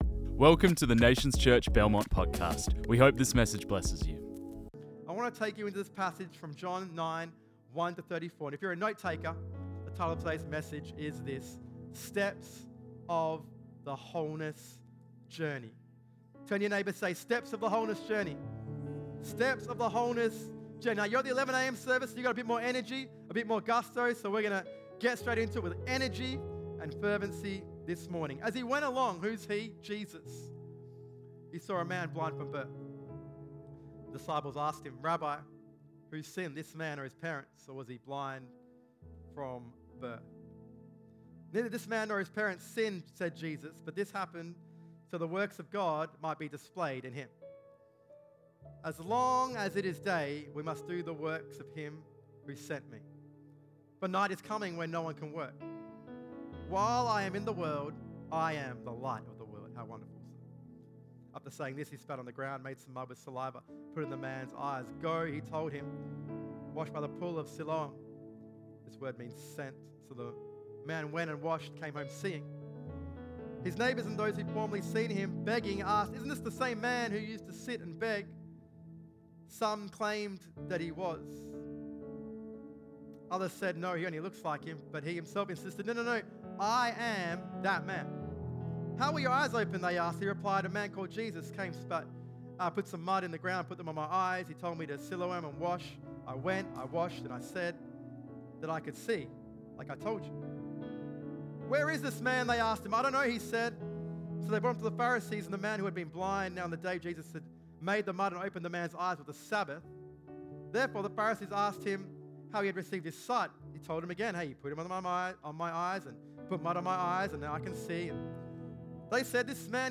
This message was preached on 10 August 2025.